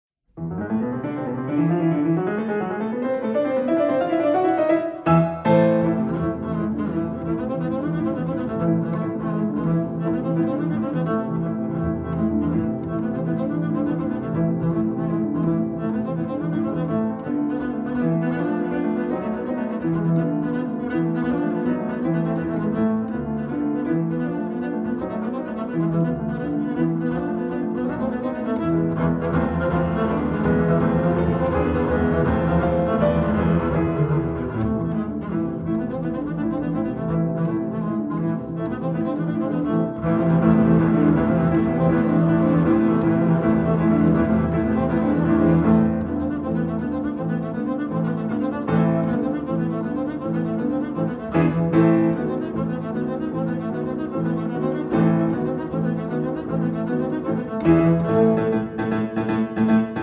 A tour de force recording of music for piano and double bass
Piano
Double Bass